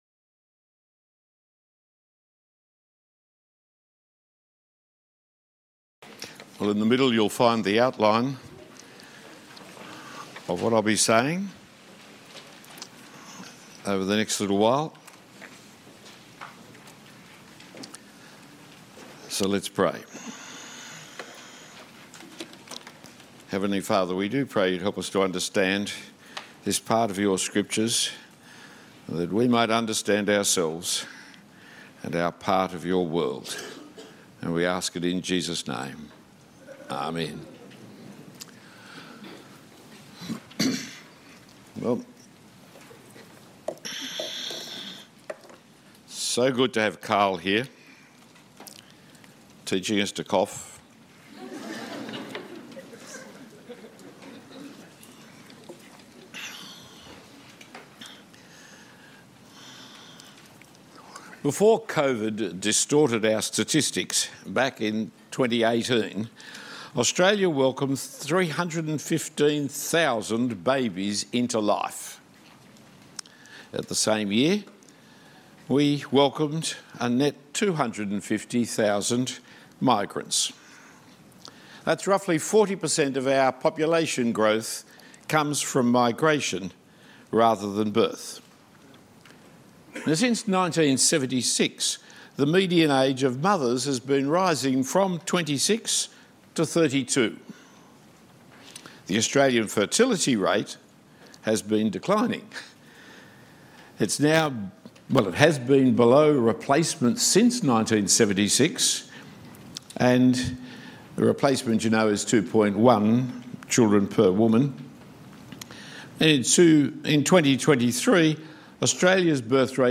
Talk 1 of 2 at the King’s Birthday Conference 2025 Biology and the Bible, The Ethics of the Creator’s Creatures.